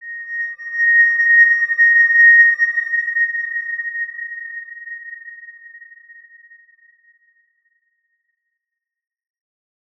X_Windwistle-A#5-mf.wav